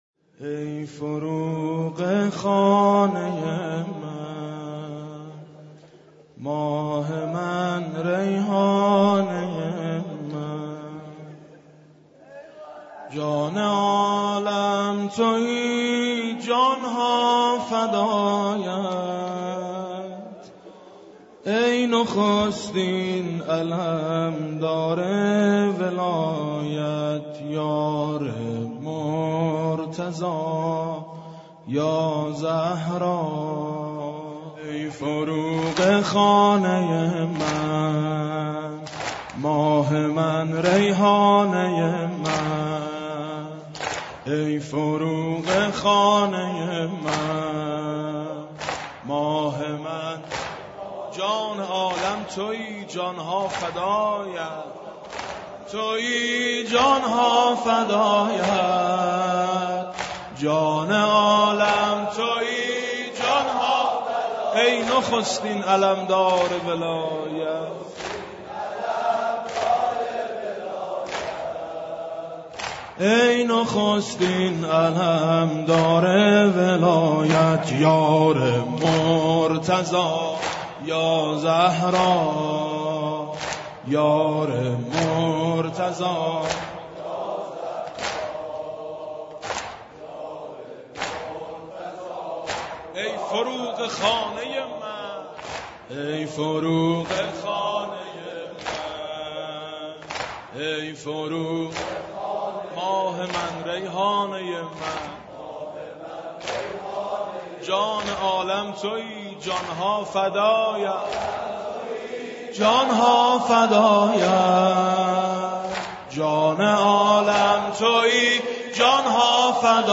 دانلود/ سه مداحی ویژه ایام فاطمیه